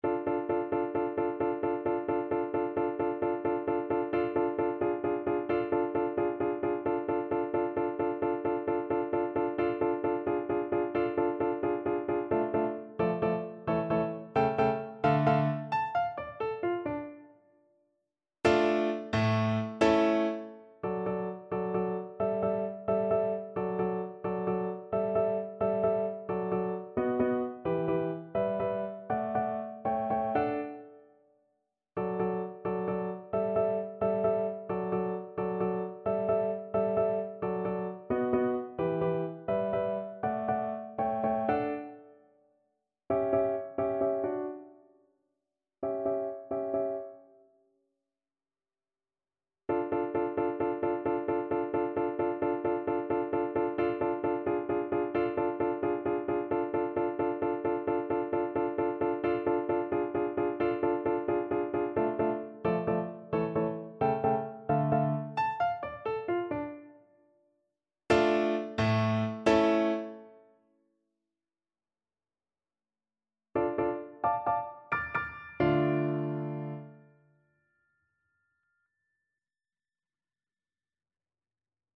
3/8 (View more 3/8 Music)